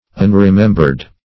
unremembered.mp3